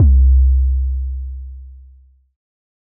808_Oneshot_Basic_C
808_Oneshot_Basic_C.wav